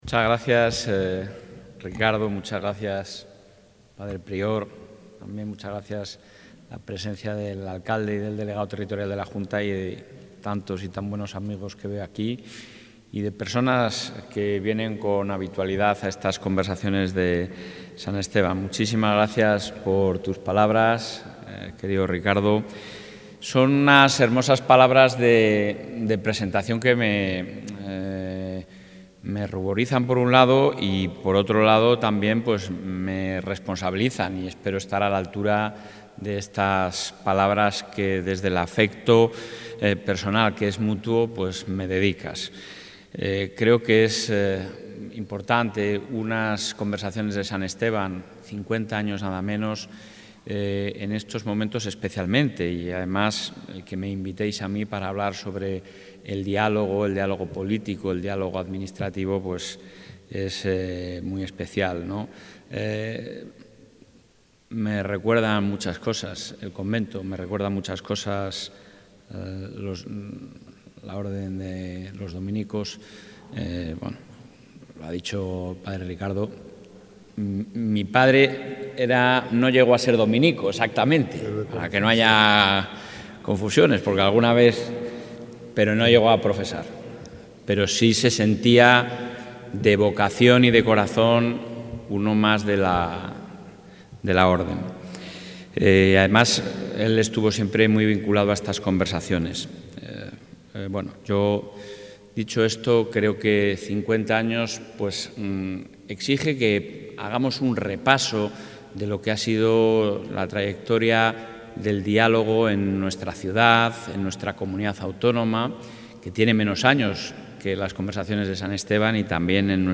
Intervención del presidente de la Junta.
El presidente de la Junta de Castilla y León ha inaugurado el nuevo curso 'Conversaciones de San Esteban' de la Facultad de Teología San Esteban, que contempla como temática principal el diálogo desde sus diferentes dimensiones